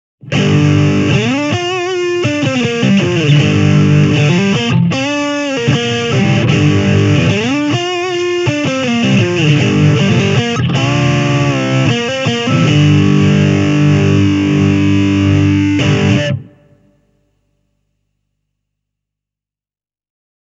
Vaikka Distortion-säädin on avattu tässä klipissä täysille, kohisee tässä klipissä pienen kombon puhdas kanava enemmän kuin Boss-uutuus (Vox Mark III):